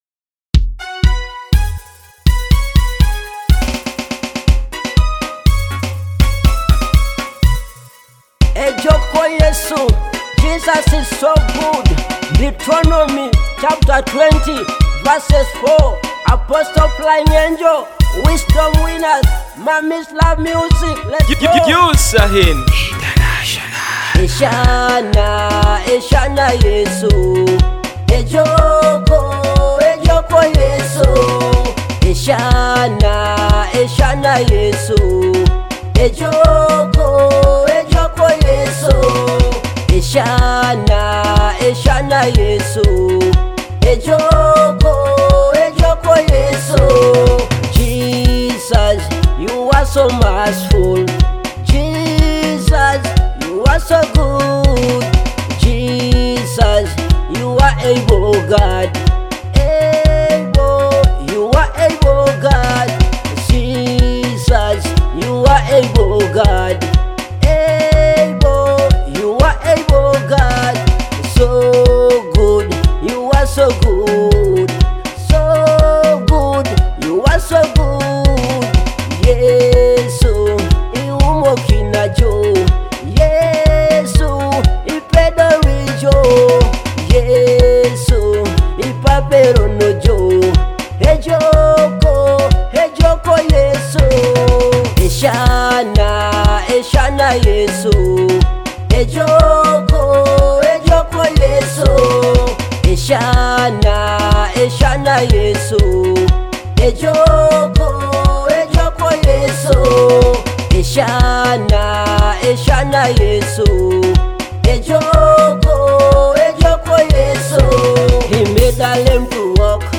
uplifting, spirit-filled track